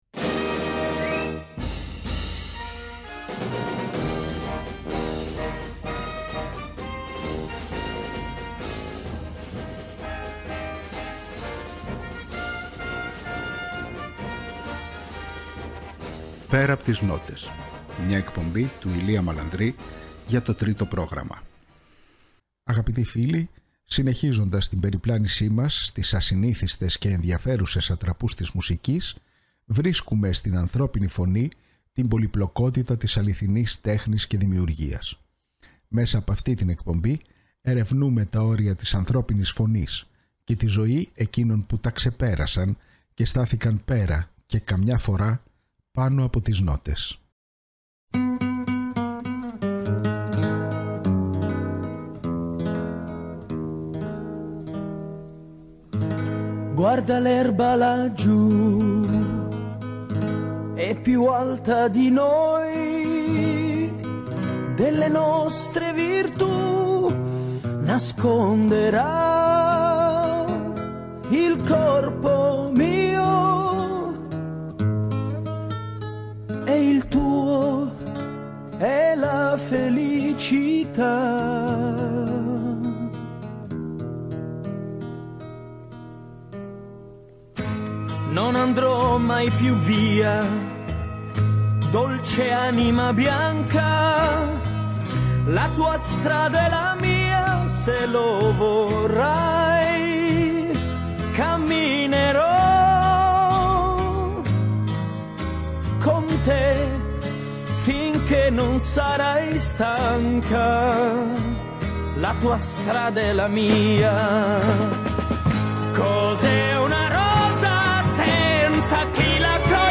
Μέσα από σπάνιο ηχητικό αρχείο συνεντεύξεων και άγνωστων ανέκδοτων ηχογραφήσεων ξετυλίγονται τα Πορτραίτα 30 καλλιτεχνών που άφησαν ένα τόσο ηχηρό στίγμα στην τέχνη καταφέρνοντας να γίνουν σημείο αναφοράς και να εγγραφούν στην ιστορική μνήμη, όχι μόνο ως ερμηνευτές αλλά και ως σύμβολα.